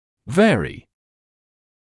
[‘veərɪ][‘вэари]изменяться; варьировать; отличаться